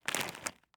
CandyEat.mp3